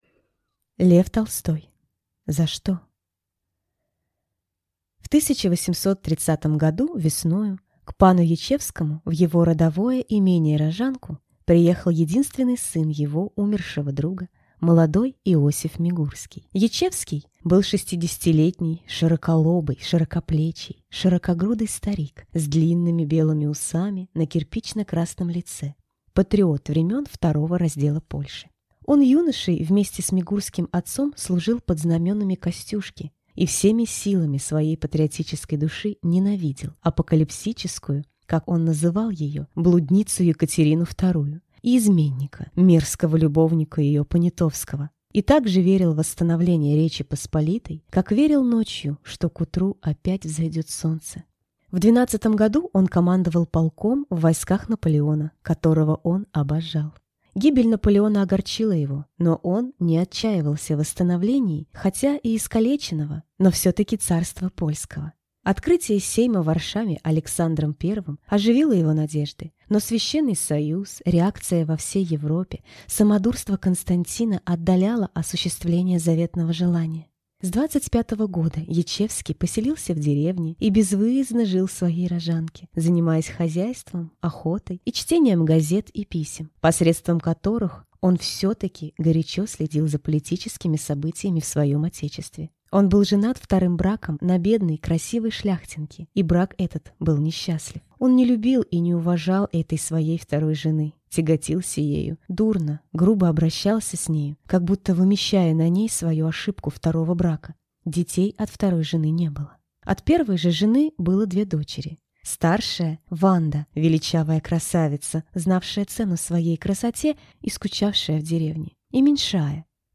Аудиокнига За что?